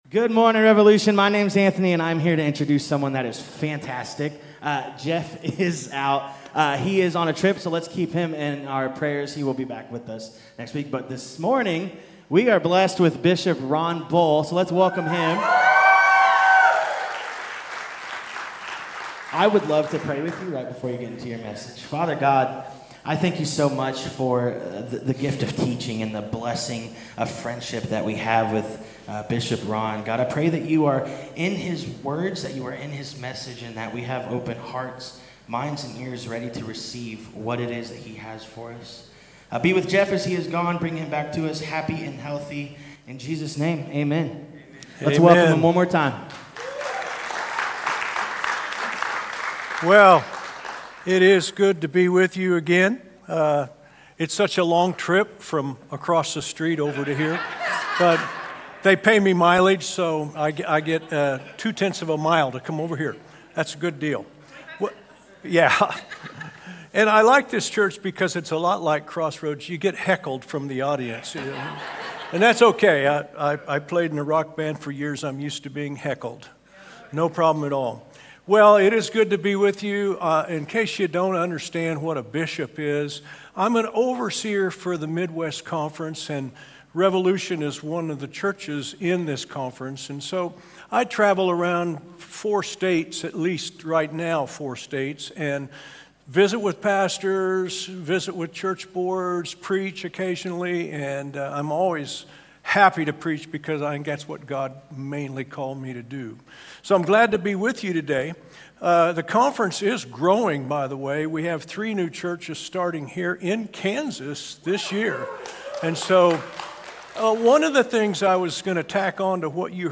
A sermon from the series "Revolution Sermon." What truly shapes your life—sin, culture, or the truth of God?